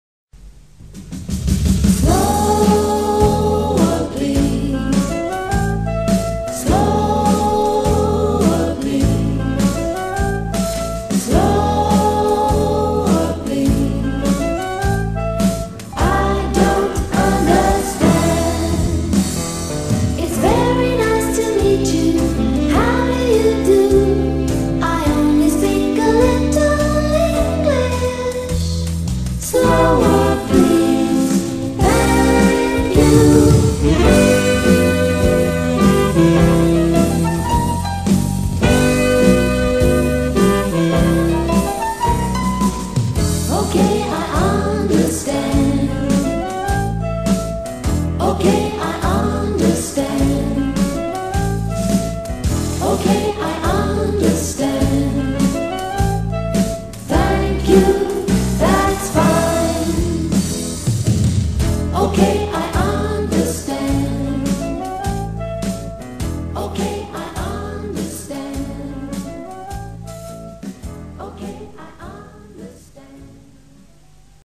Aprende Gramática Cantando
con esta canción no comercial